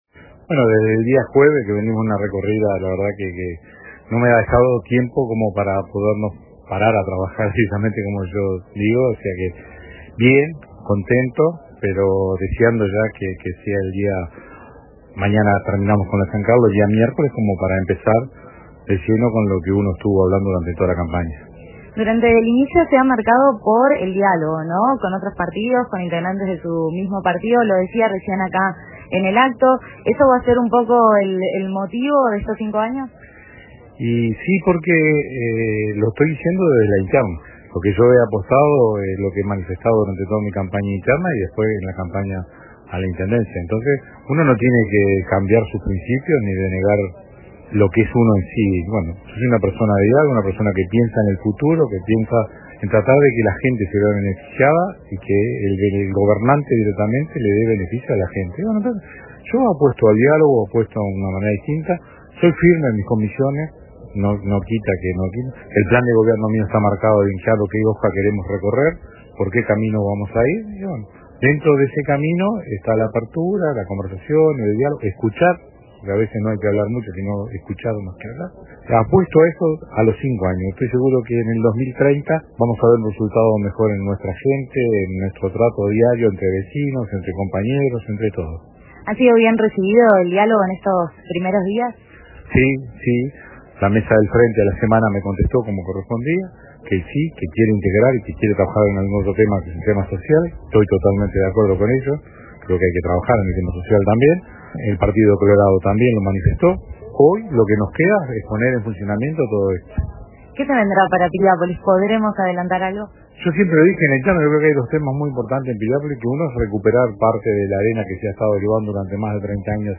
El intendente de Maldonado, Miguel Abella, participó este lunes 14 de julio en la ceremonia de asunción del alcalde reelecto de Piriápolis, René Graña.